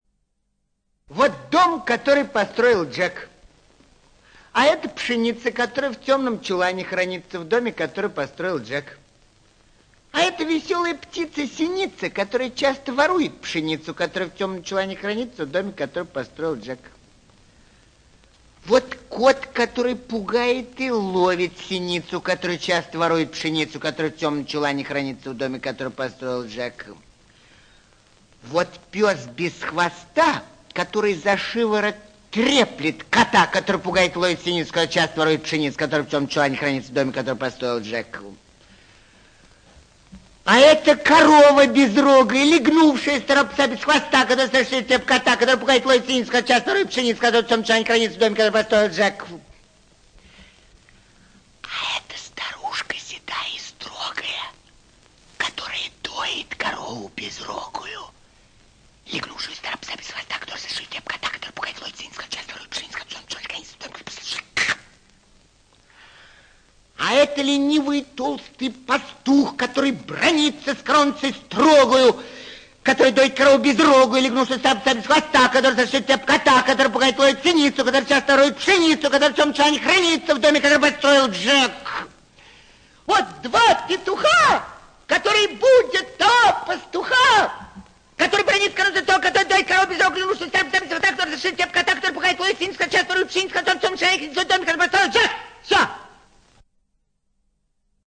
ЧитаетИльинский И.